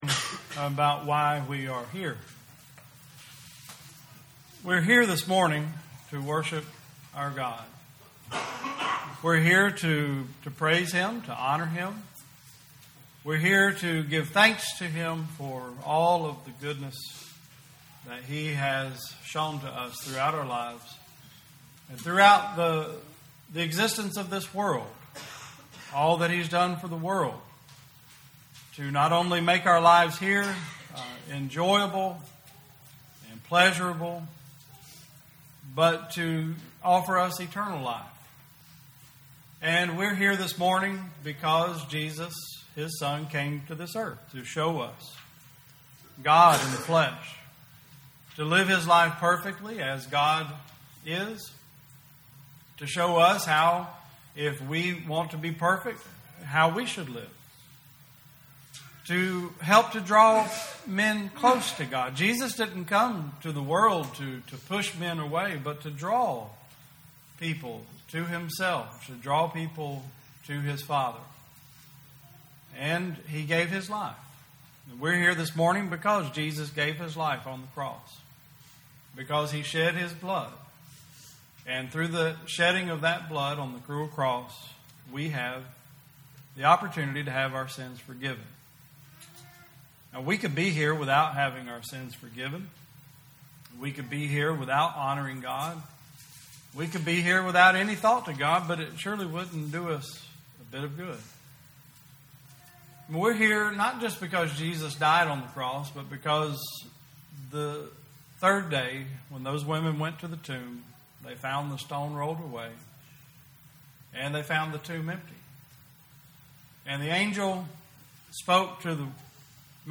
2019 Service Type: Sunday Service Topics: Alcohol , carousing , Drinking , Temptation « What Does the Bible Say About Gambling?